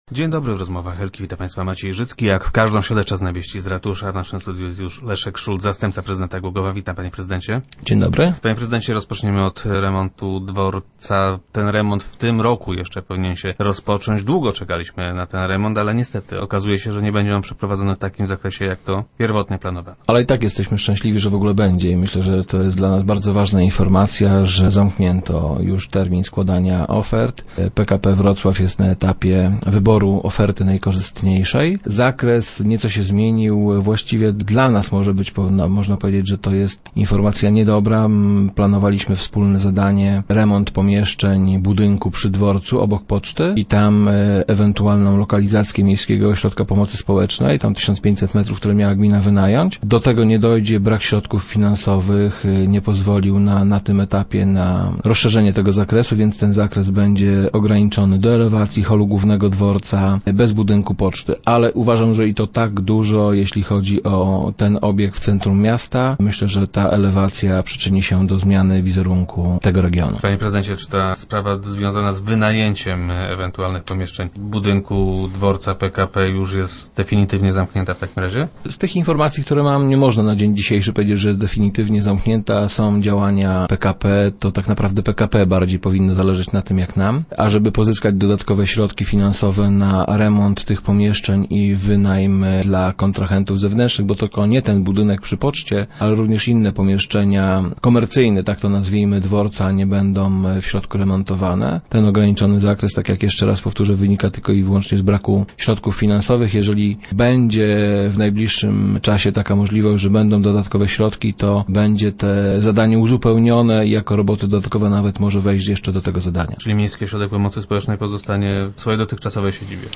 Brak pieniędzy nie pozwolił na tym etapie na taki zakres remontu - informuje wiceprezydent Szulc, który był gościem Rozmów Elki.